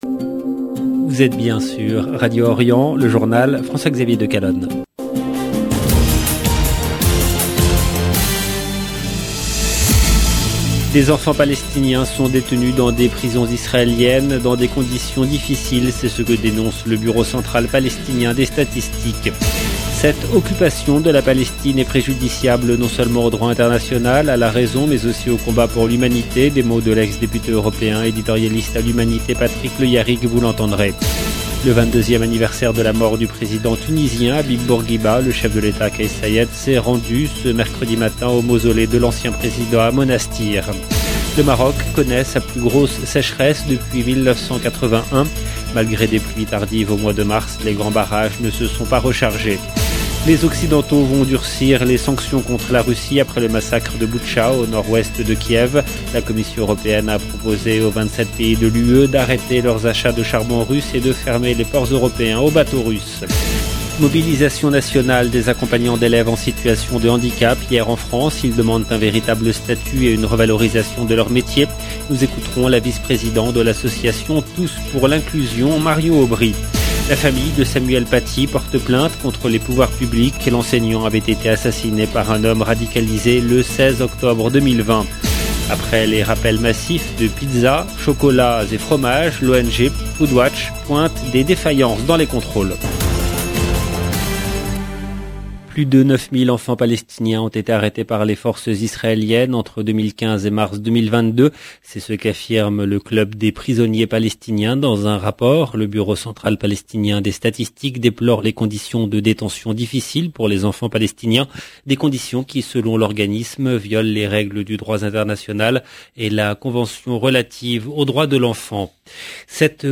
LE JOURNAL EN LANGUE FRANCAISE DU 6/04/22